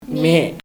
[ɲe] noun brother
Dialect: Hill Remo